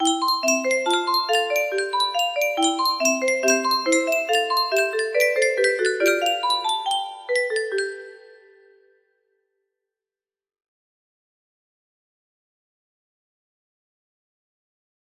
Álmanakkin music box melody